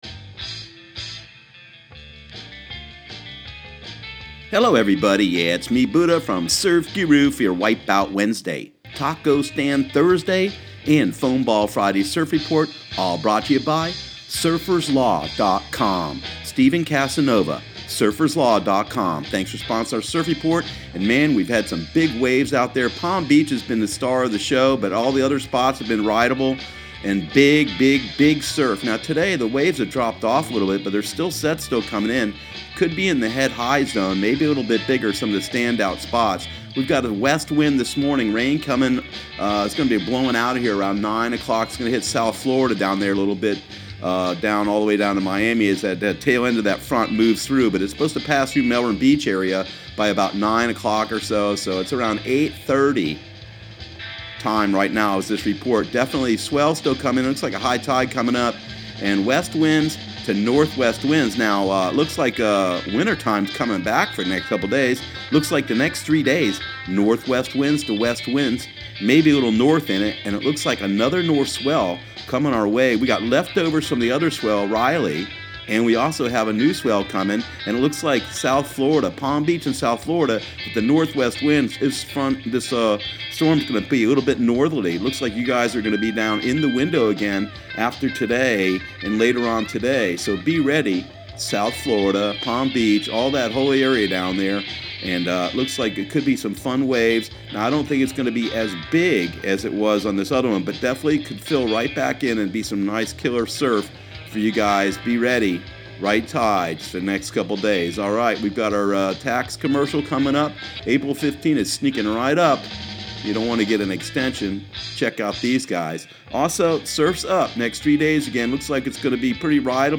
Surf Guru Surf Report and Forecast 03/07/2018 Audio surf report and surf forecast on March 07 for Central Florida and the Southeast.